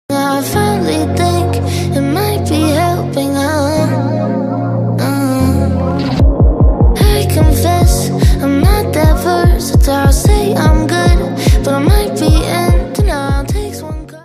the beats are bad, i dont use auto beats i do them manually